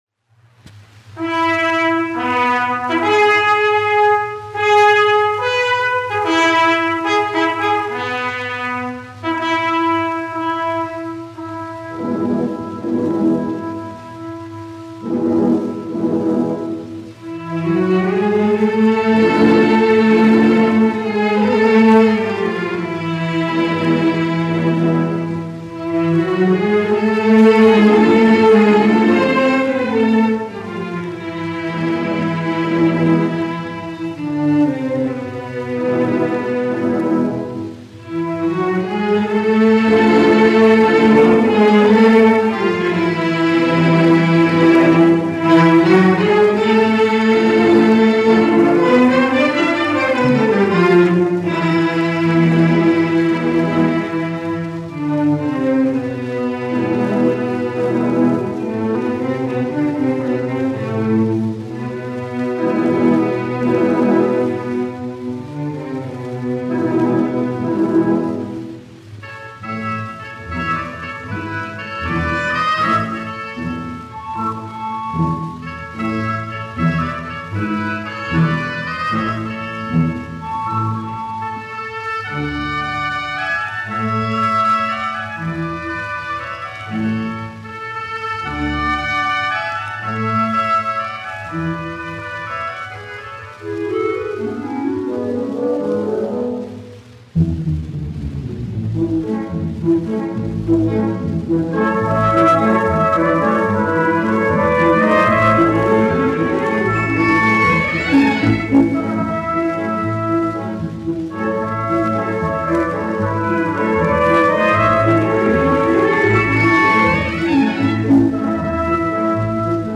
Staatskapelle Berlin, izpildītājs
Blech, Leo, 1871-1958, diriģents
1 skpl. : analogs, 78 apgr/min, mono ; 25 cm
Orķestra mūzika
Latvijas vēsturiskie šellaka skaņuplašu ieraksti (Kolekcija)